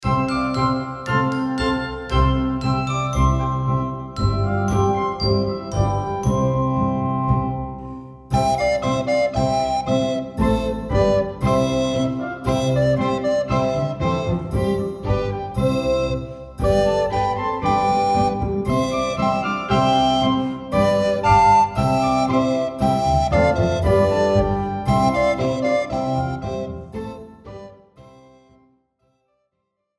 Besetzung: 1-2 Altblockflöten